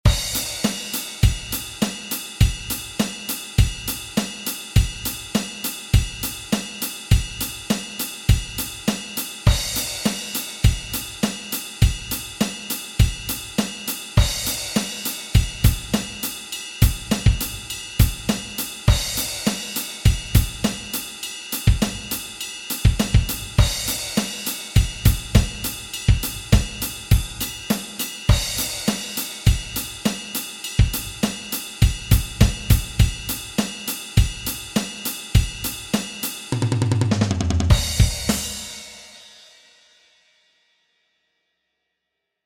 In den ersten sechs Takten wird ein Rhythmus eingeübt.
Die Bass Drum wird anschließend verändert.